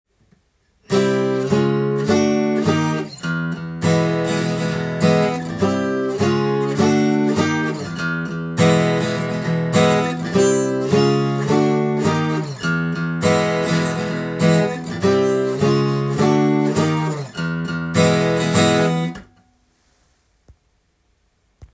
アコギで譜面通り弾いてみました